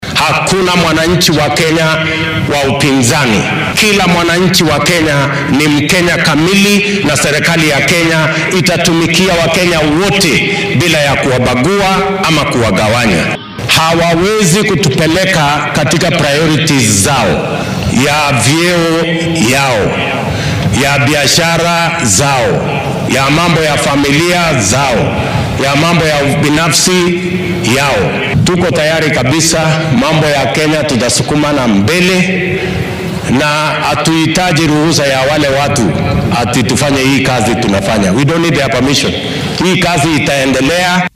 Madaxweynaha dalka William Ruto oo shalay dadweynaha kula hadlay fagaaraha Ole Ntimama Stadium ee ismaamulka Narok ayaa sheegay in waxyaabaha ay sameynayaan siyaasiyiinta mucaaradka iyo dhaliilaha ay u jeedinayaan dowladda dhexe aynan marnaba carqaladeyn doonin habsami u socodka qorshihiisa horumarineed.